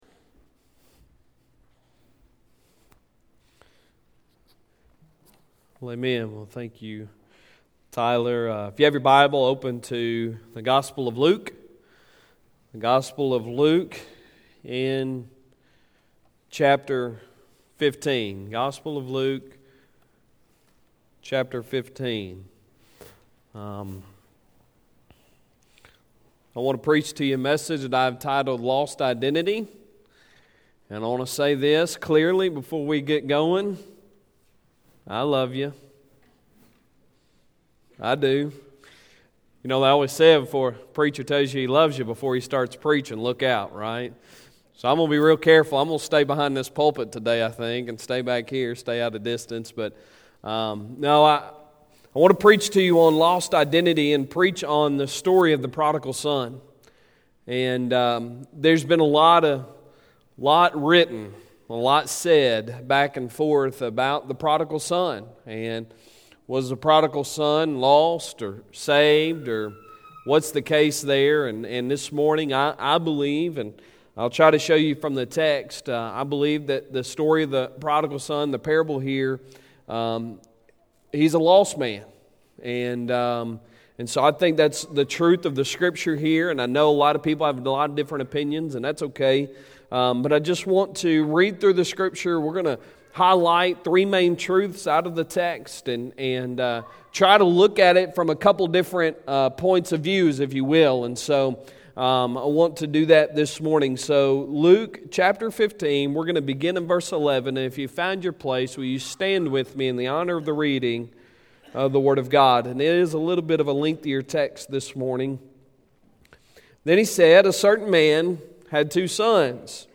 Sunday Sermon November 10, 2019